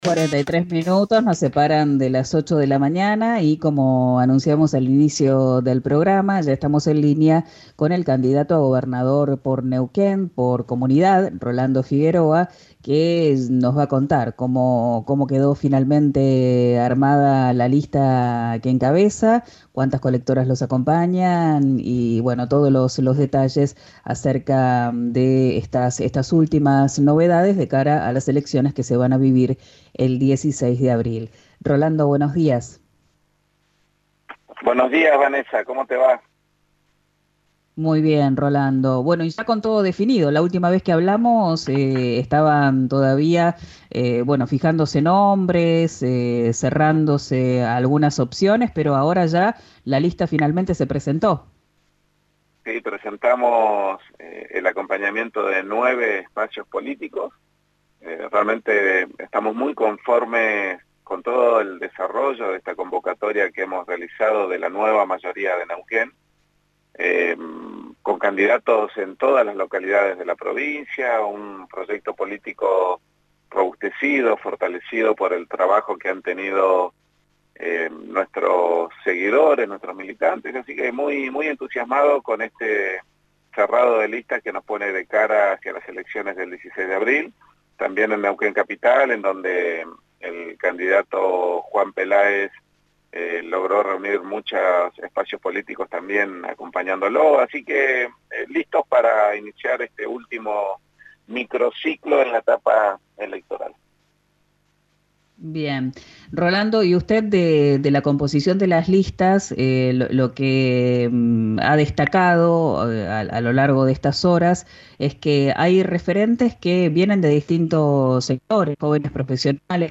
En diálogo con «Quién dijo verano», por RÍO NEGRO RADIO, Figueroa señaló que presentaron candidatos en toda la provincia al cierre del plazo para inscribirse en el juzgado electoral.